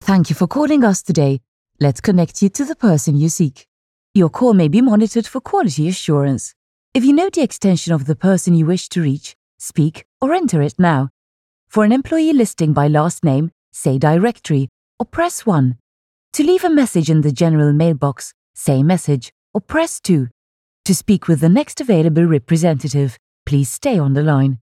Maltese Accented English: A Harmonious Blend